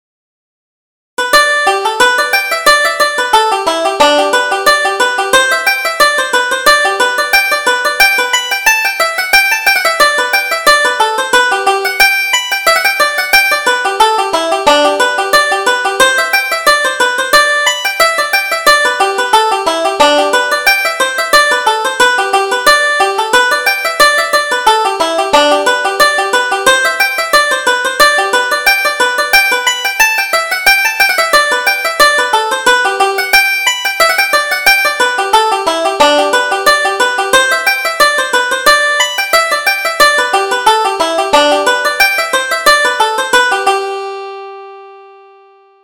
Reel: King of the Clans